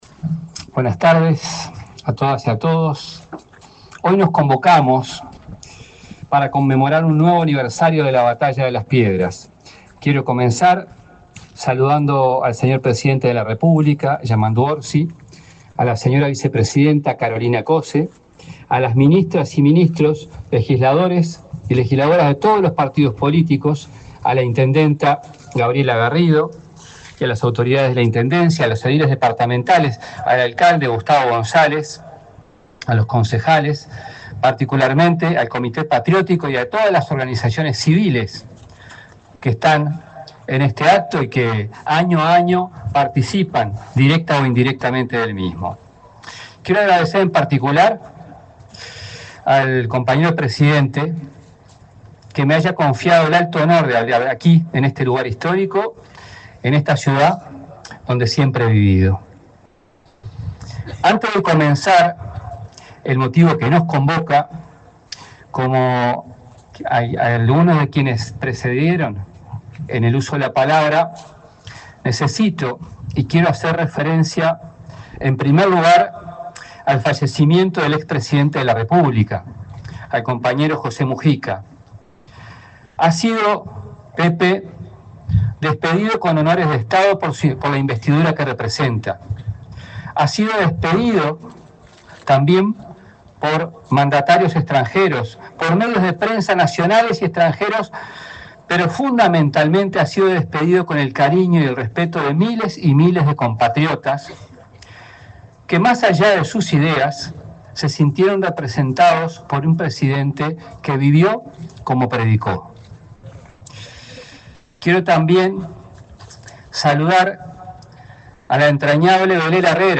Discurso del ministro de Educación y Cultura, José Carlos Mahía
El ministro de Educación y Cultura, José Carlos Mahía, fue el orador central del acto conmemorativo de los 214 años de la Batalla de Las Piedras.